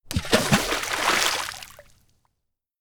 water-splash.ogg